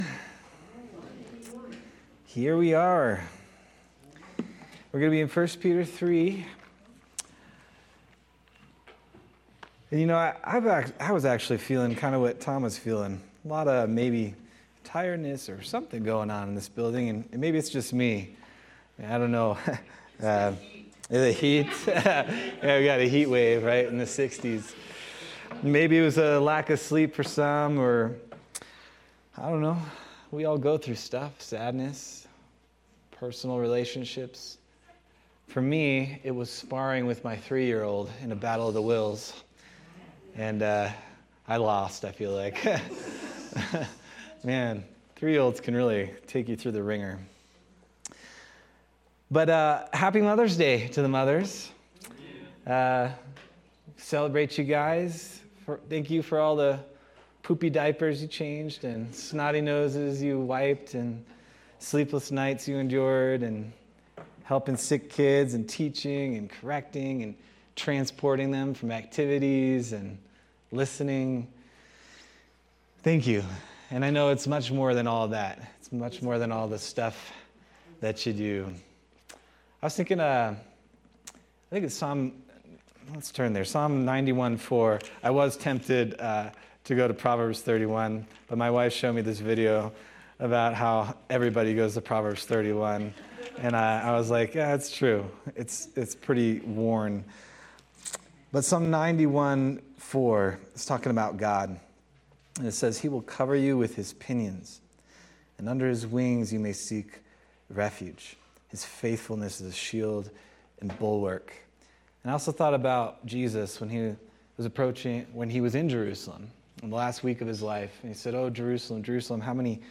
May 11th, 2025 Sermon